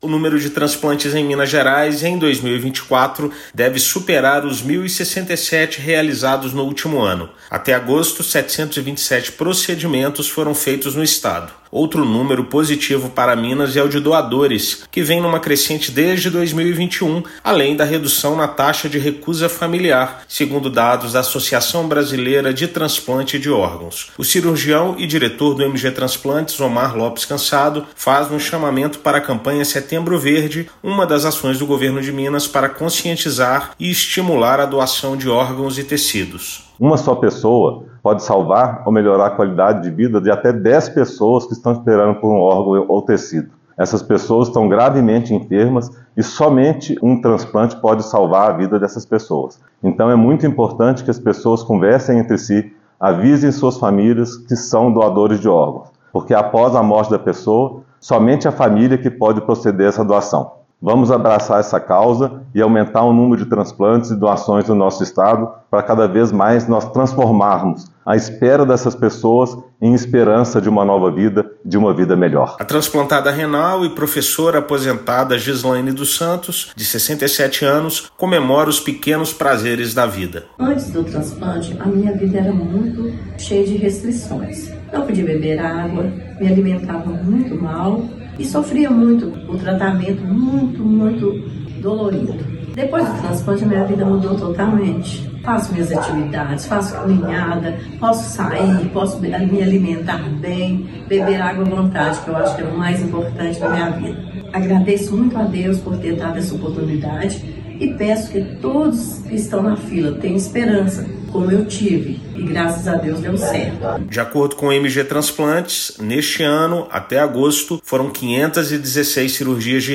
Incentivo do Governo do Estado, campanha Setembro Verde e queda na taxa de recusa têm impacto no número de doadores de múltiplos órgãos, índice que cresceu mais de 20% em 3 anos. Ouça matéria de rádio.